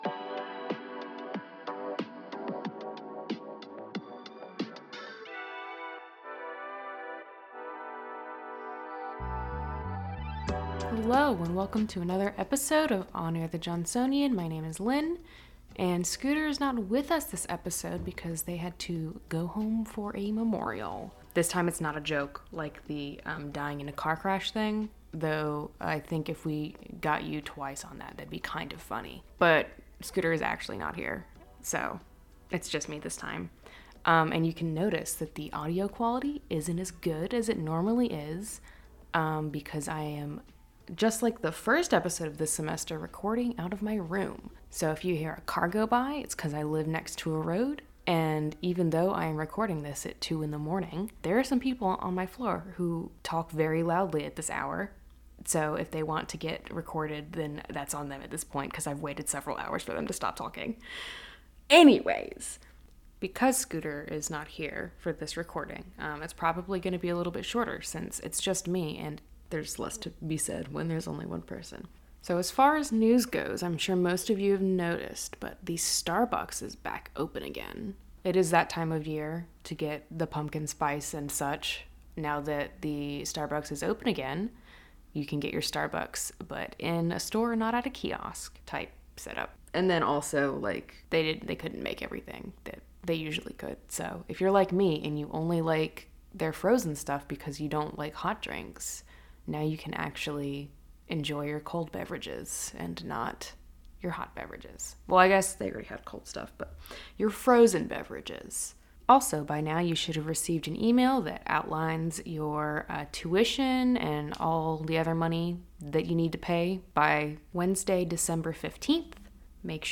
This week, your host is left all alone to talk about everyone’s favorite subject: final exams.